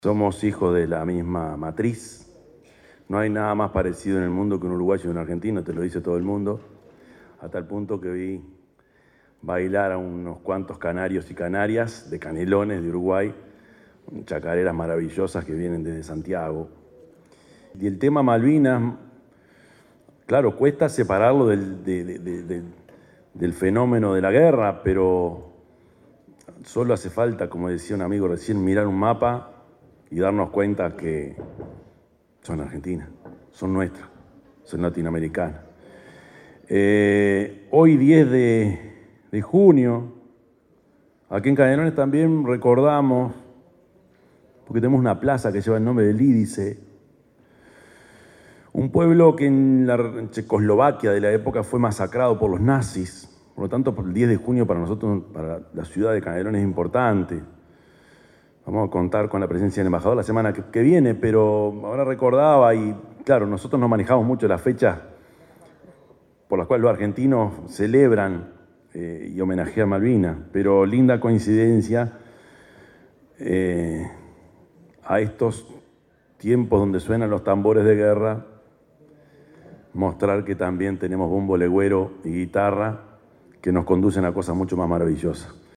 En el Complejo Cultural Politeama – Teatro Atahualpa del Cioppo de la ciudad de Canelones, con el auspicio de la Embajada de Argentina en Uruguay, se desarrolló el encuentro cultural Malvinas no une, cuyo objetivo fue recolectar alimentos para ollas populares del departamento de Canelones.
encuentro_cultural_malvinas_nos_une.mp3